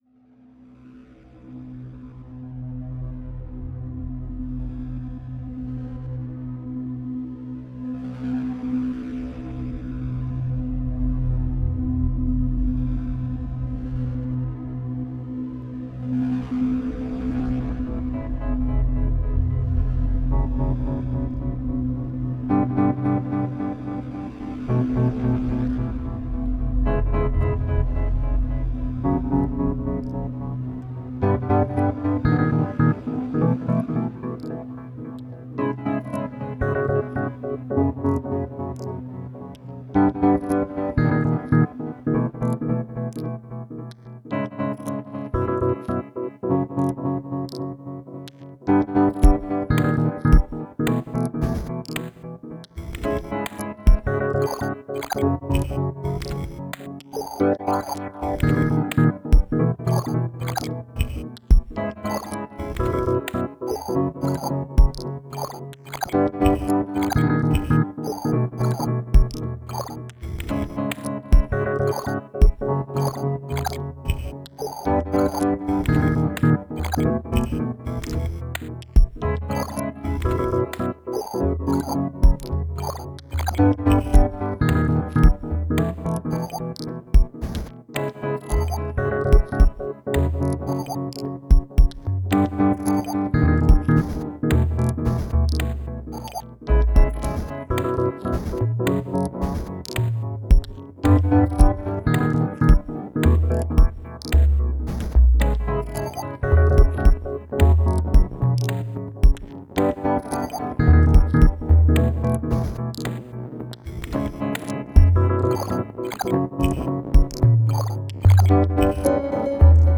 Excellent and extremely elegant electronic music.»